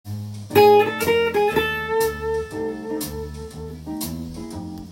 譜面通り弾いてみました
ブルースの王道フレーズになっています。
マイナーのようなメジャーのような中間的なフレーズです。